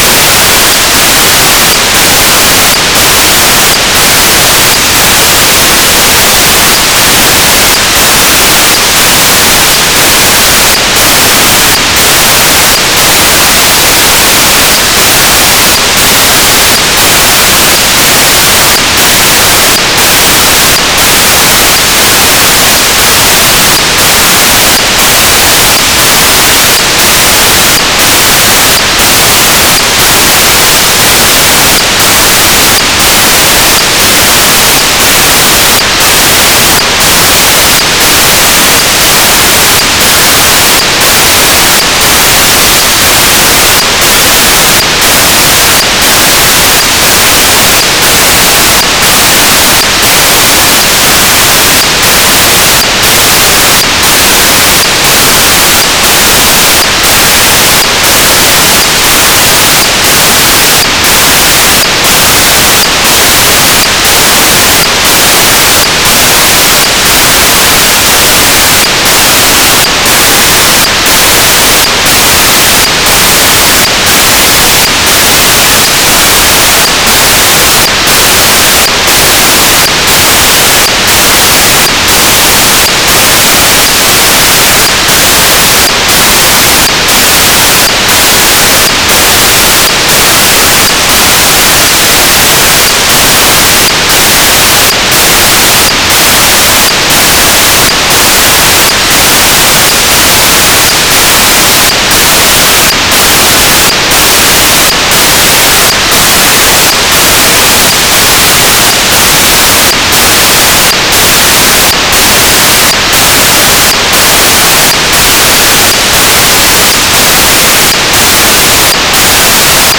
FM demodulated 48 kHz mono 16 bits .wav file.